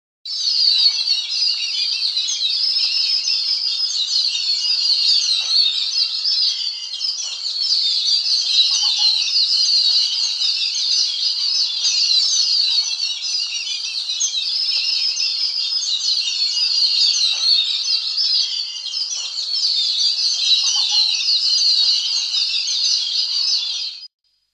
Yellow-billed Babbler – alarm calls
3.Yellow-billed-babbler_alarm.mp3